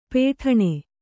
♪ pēṭhaṇe